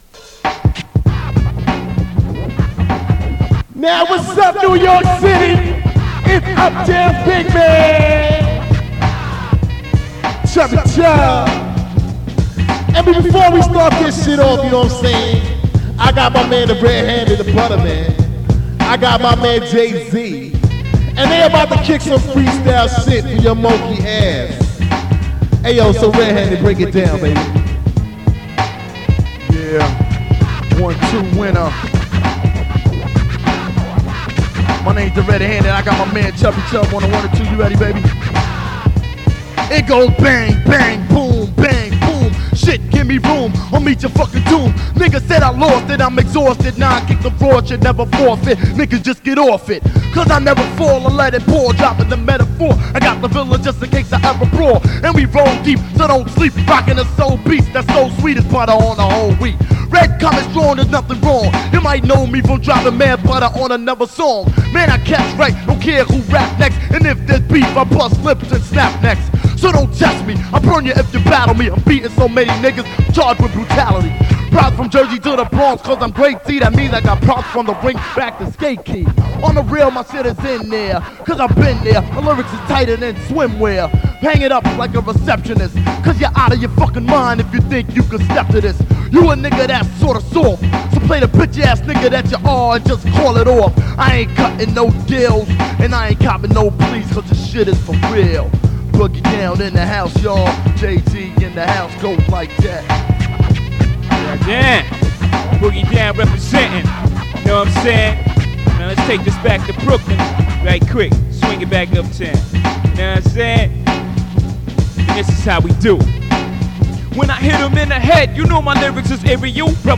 Hip Hop, Music